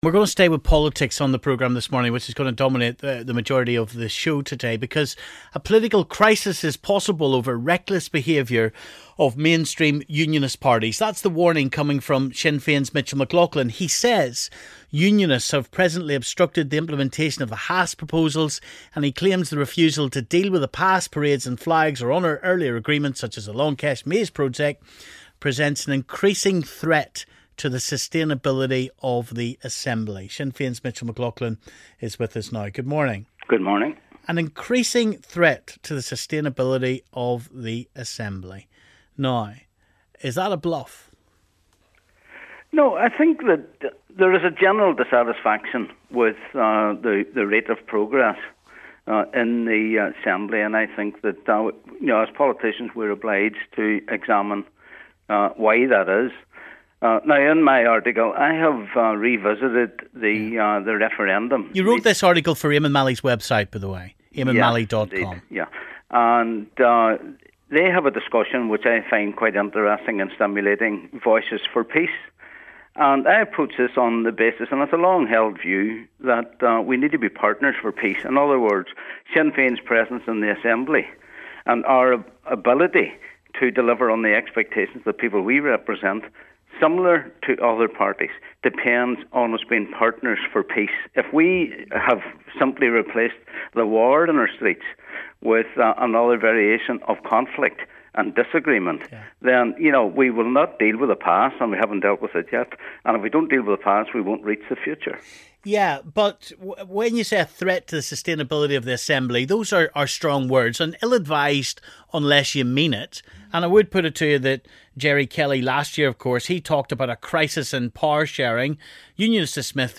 Sinn Fein's Mitchell McLoughlin and the DUP's Gregory Campbell discuss the possibilities of a crisis.